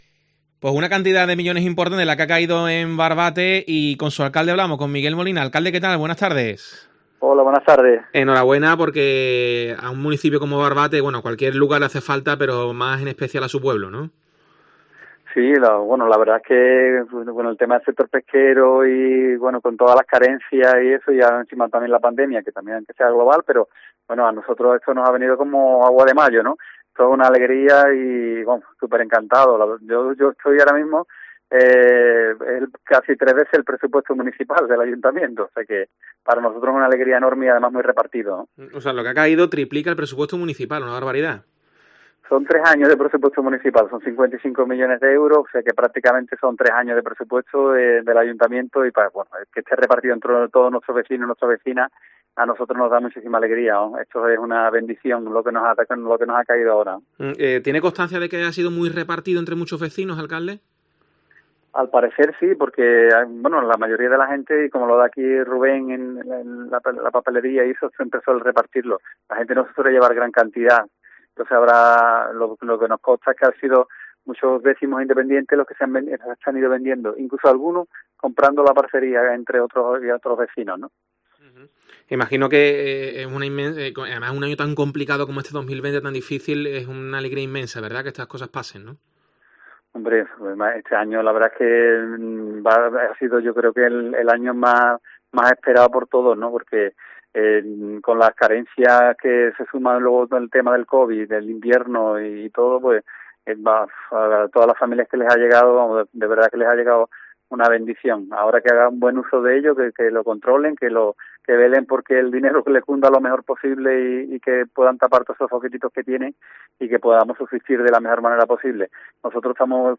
Miguel Molina, alcalde de Barbate, reconoce su felicidad por la Lotería de Navidad